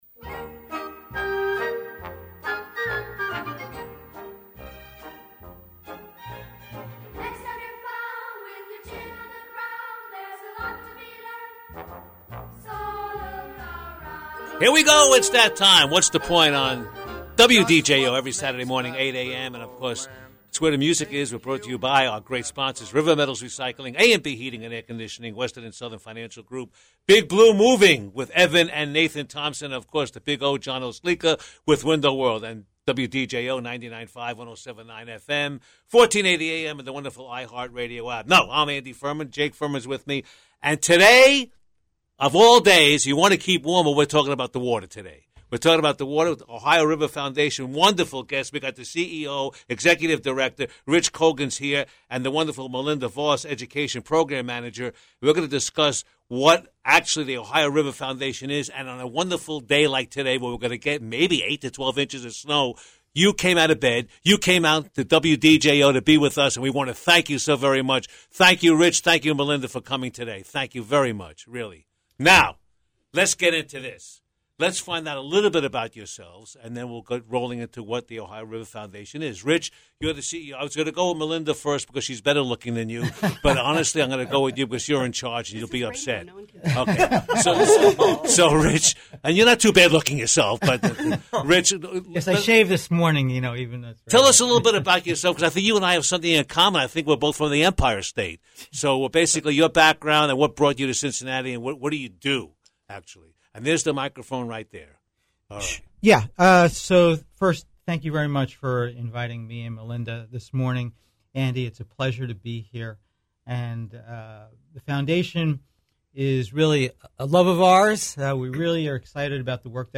They discussed the mission and founding of ORF, ORF’s education programs for children, and what ORF has coming up. Listen to the full interview below!